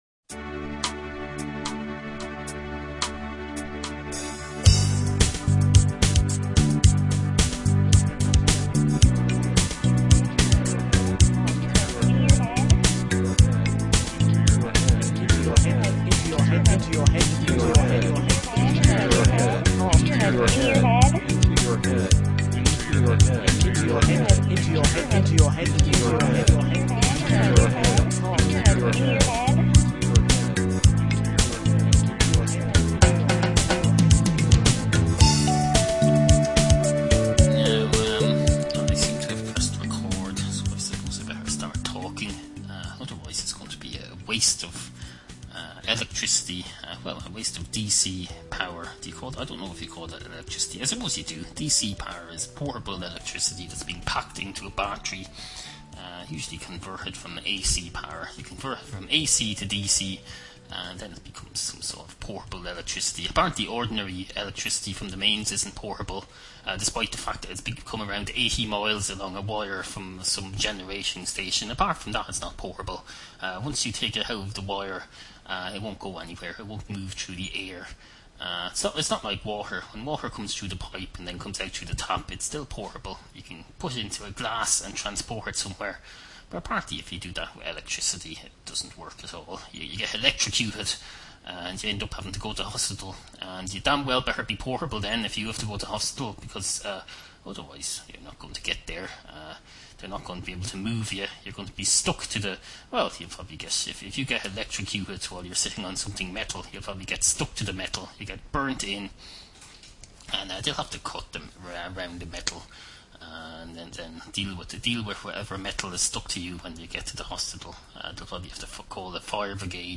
Obscure 21st Century Irish audio comedy series